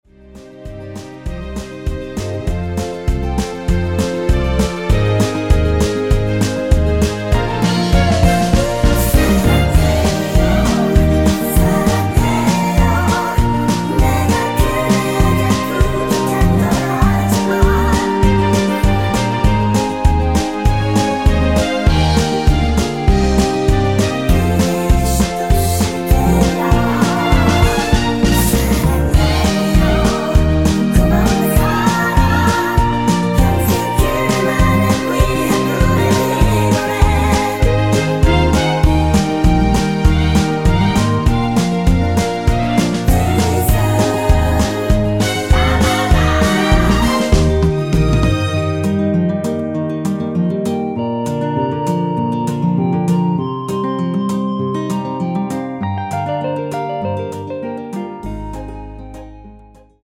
전주가 길어서 8마디로 편곡 하였으며
원키에서(+3)올린 (1절+후렴)으로 진행되는 멜로디와 코러스 포함된 MR입니다.
Eb
앞부분30초, 뒷부분30초씩 편집해서 올려 드리고 있습니다.
중간에 음이 끈어지고 다시 나오는 이유는